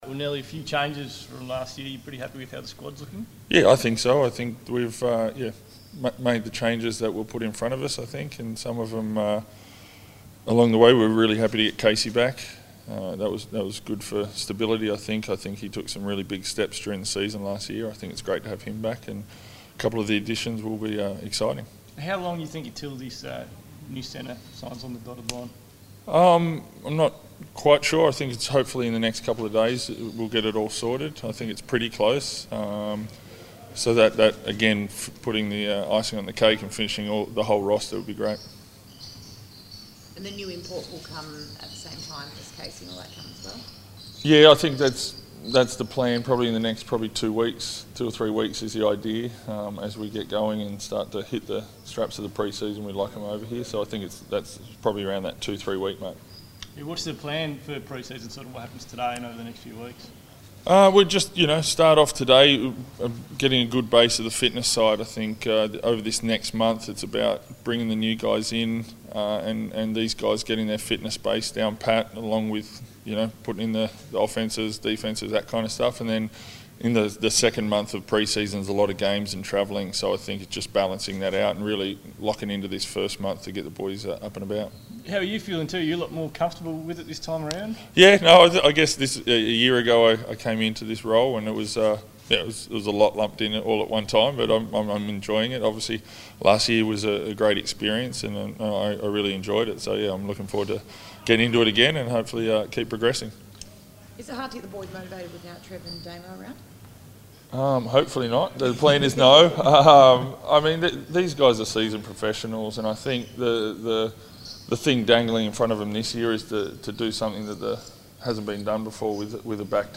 Matt Nielsen press conference - 1 August 2016
Assistant coach Matt Nielsen speaks to the media on the Wildcats first day of pre-season.